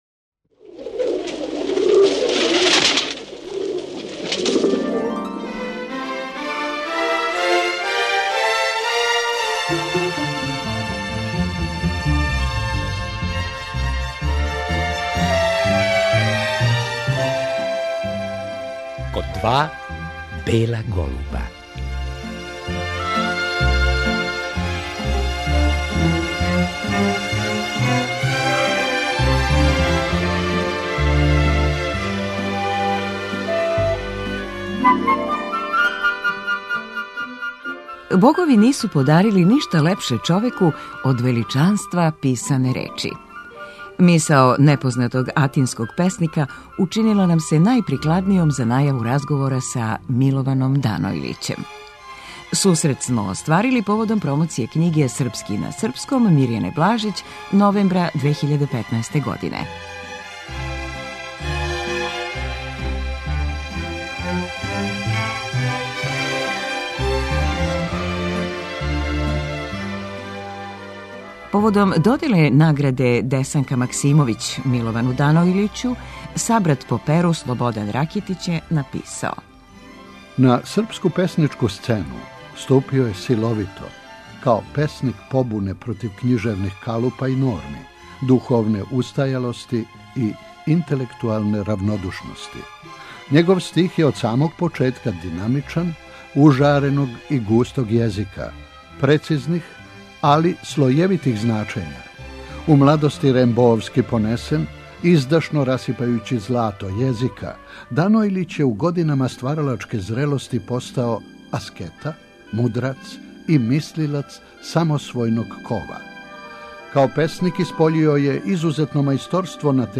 да са академиком поразговарамо на какав је пријем наишла његова књига "Мука с речима", седамдесетих година прошлог века.